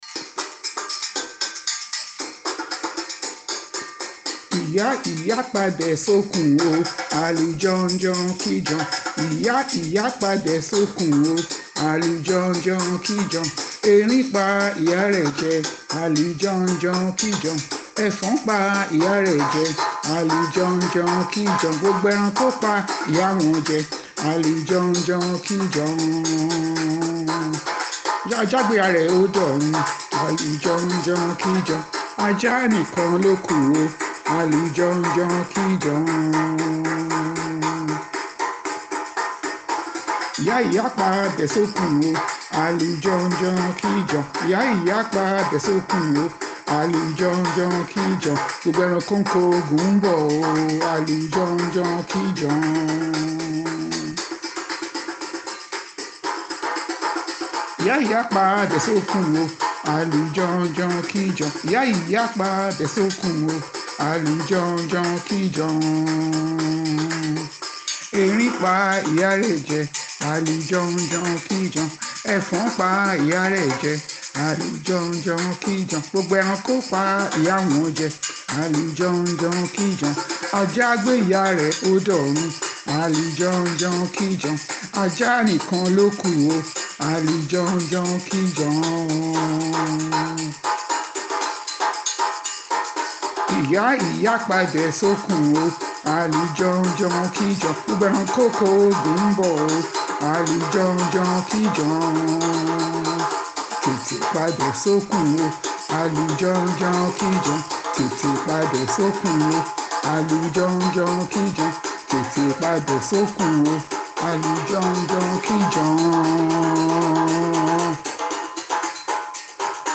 He started to sing another song quickly, asking his mother to cut the rope, so that Ijapa would fall down.